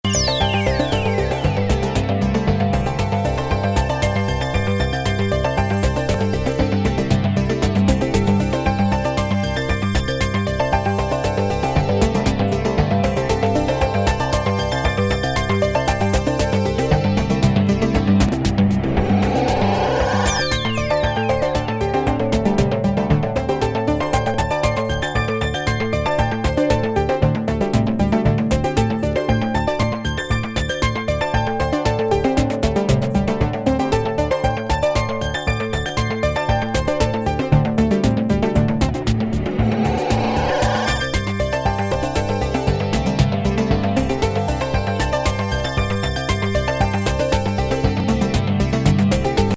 ループに、幾重にも交差するシンセ・シーケンスで構築されたディープ・コズミック